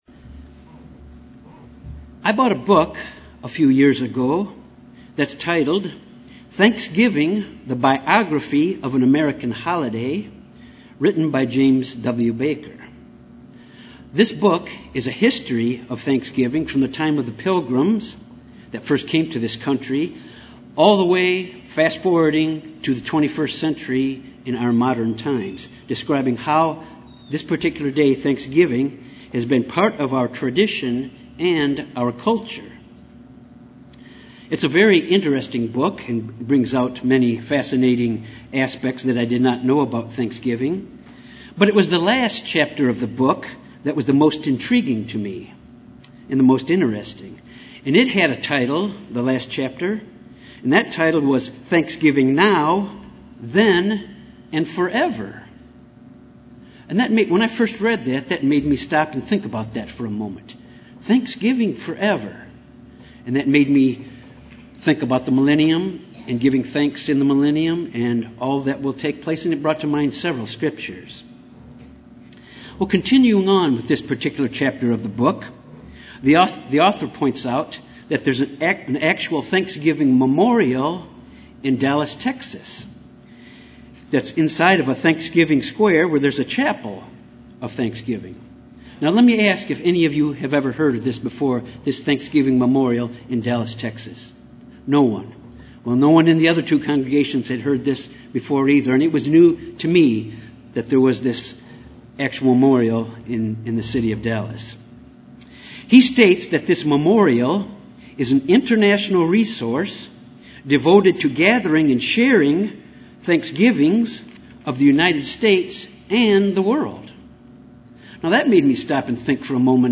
Given in Little Rock, AR
UCG Sermon Studying the bible?